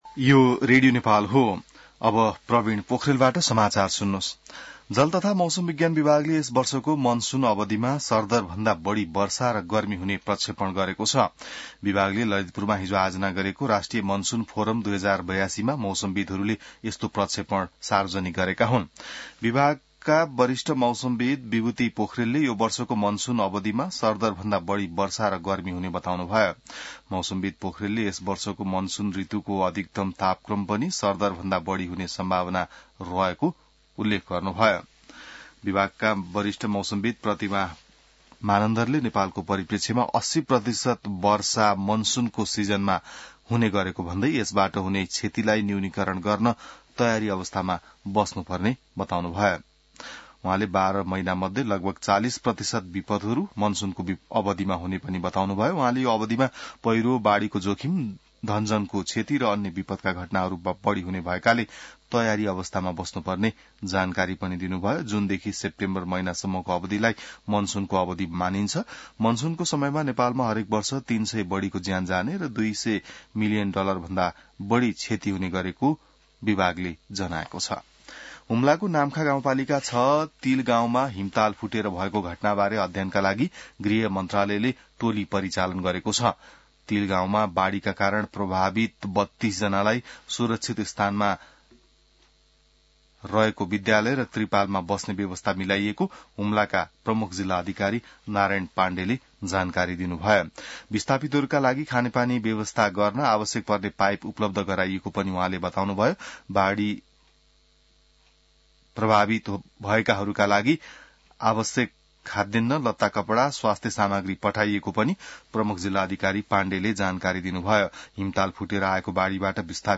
बिहान ६ बजेको नेपाली समाचार : ८ जेठ , २०८२